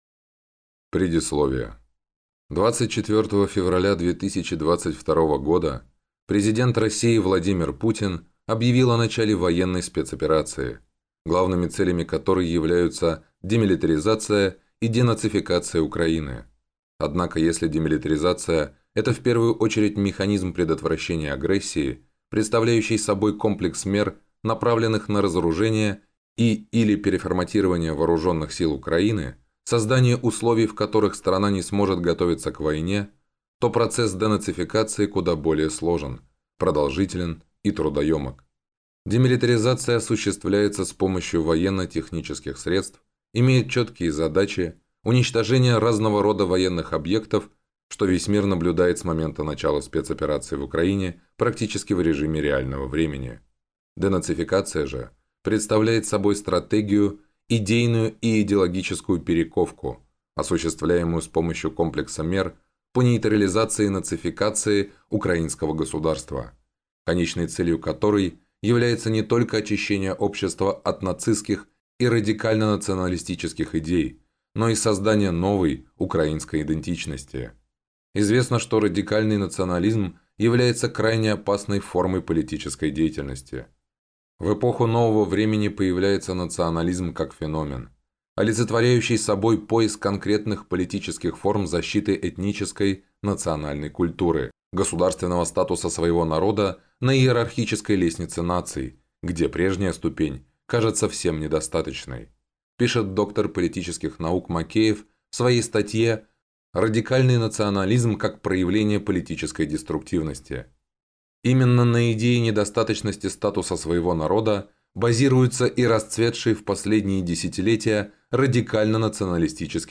Аудиокнига Проект «Украина»: из прошлого в будущее | Библиотека аудиокниг